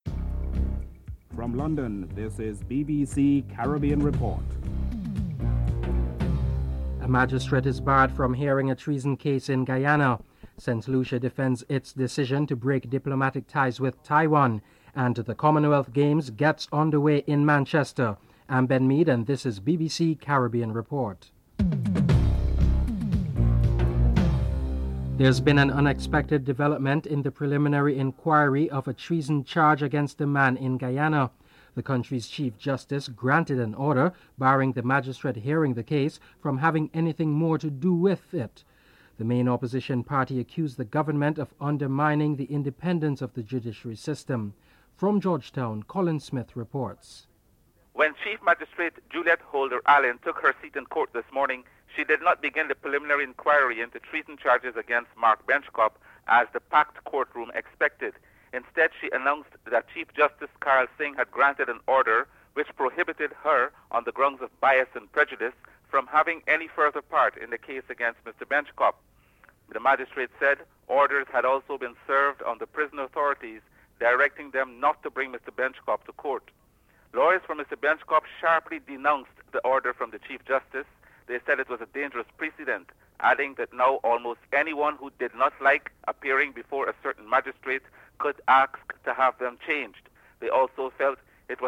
1. Headlines: (00:00-00:23)